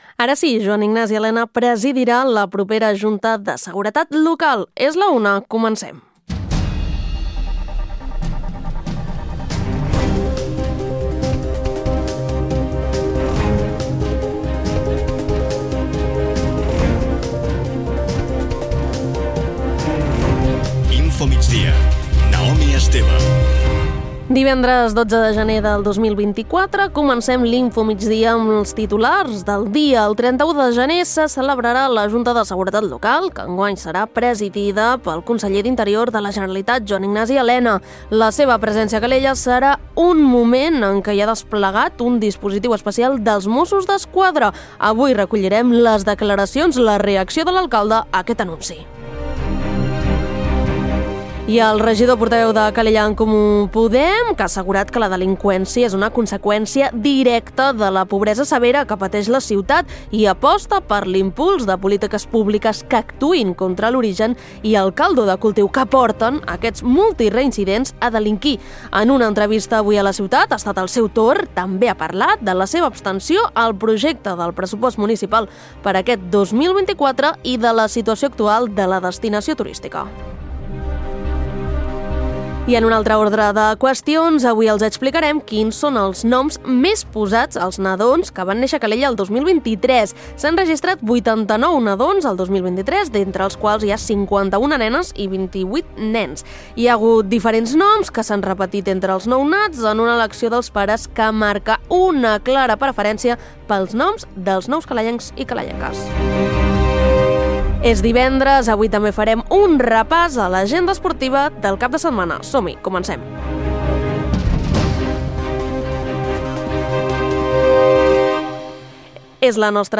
Notícies d’actualitat local i comarcal.